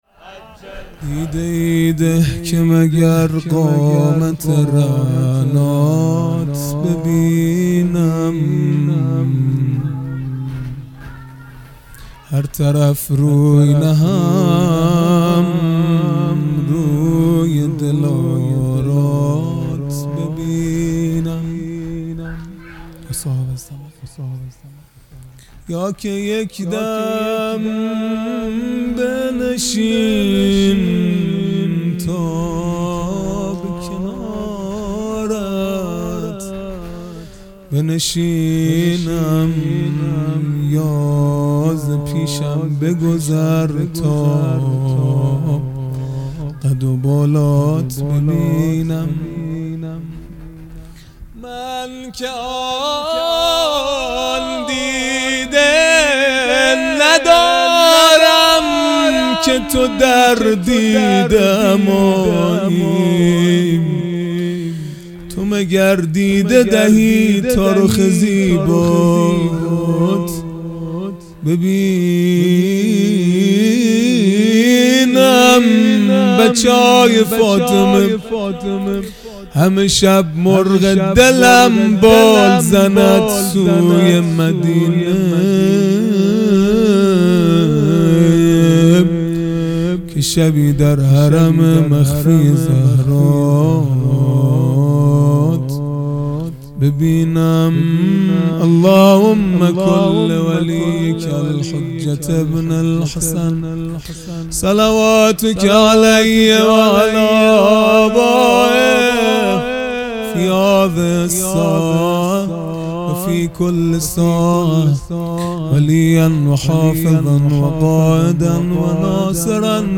مناجات پایانی | دیده ای ده که مگر قامت رعنات ببینم
دهه اول محرم الحرام ۱۴۴٢ | شب هفتم | چهارشنبه ۵ شهریور ٩٩